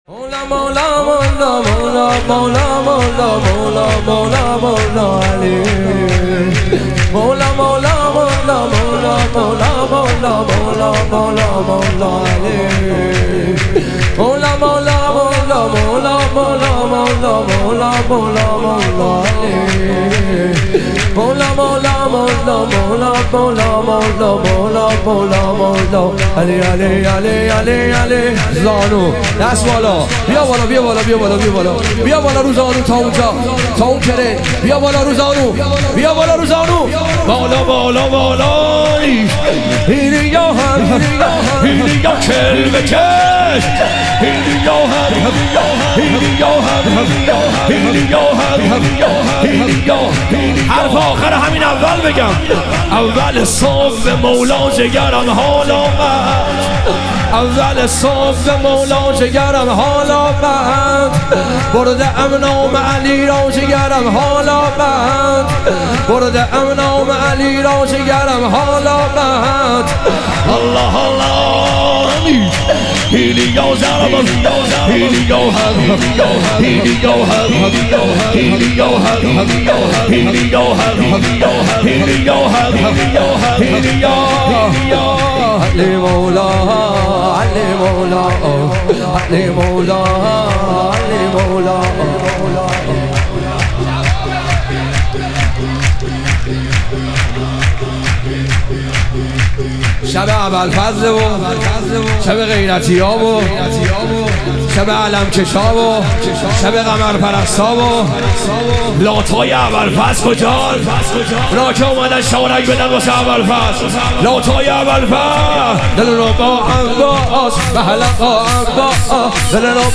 ظهور وجود مقدس حضرت عباس علیه السلام - شور